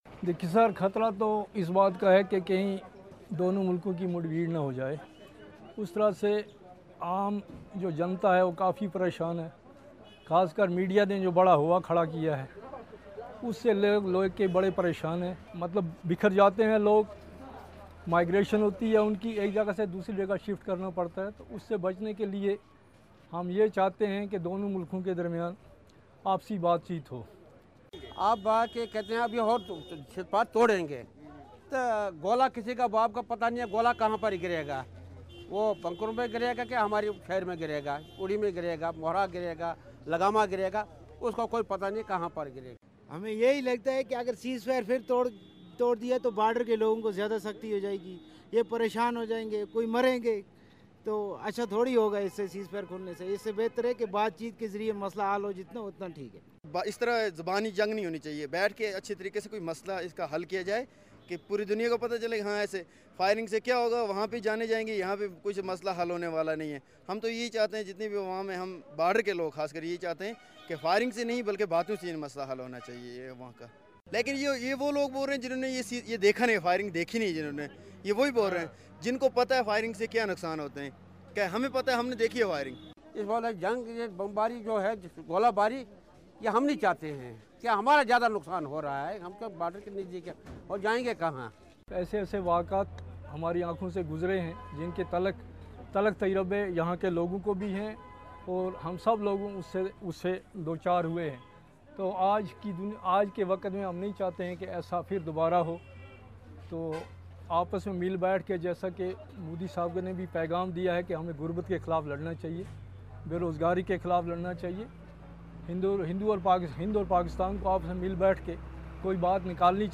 कश्मीर में सरहद के नजदीक रहने वालों से बात की